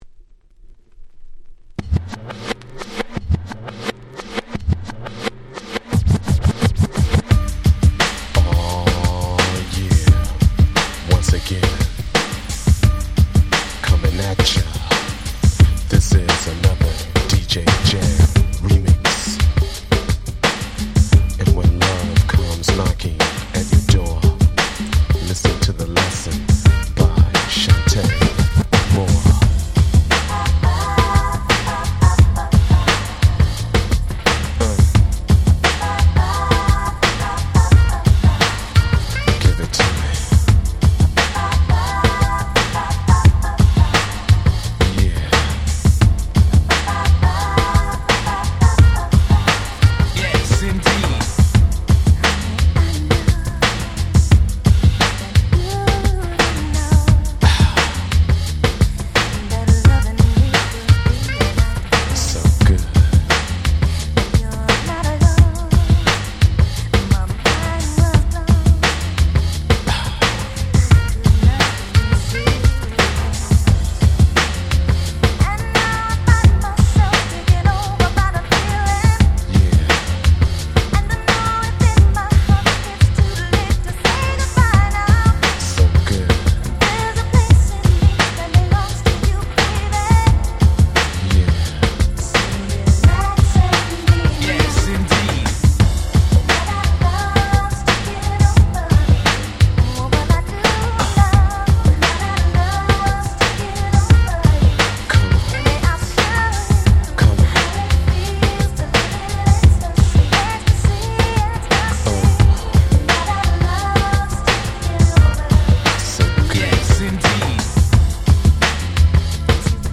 92' Smash Hit R&B !!